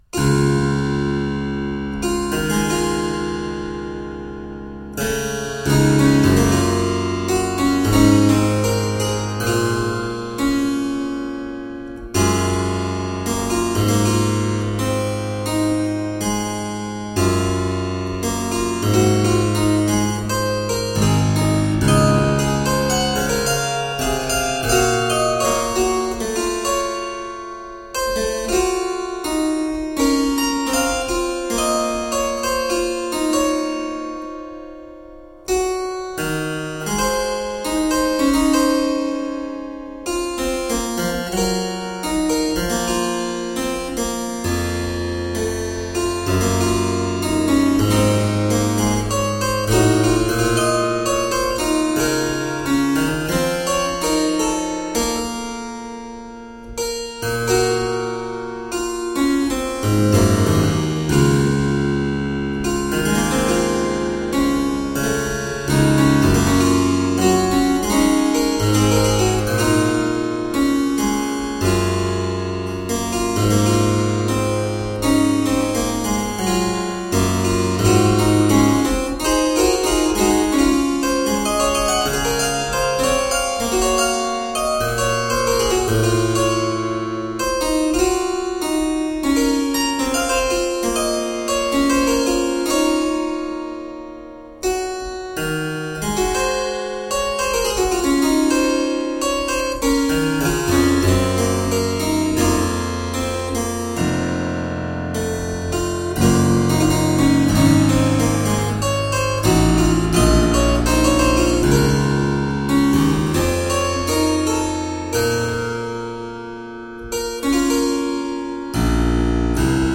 Harpsichord and fortepiano classics.
Rich tones, reflective work.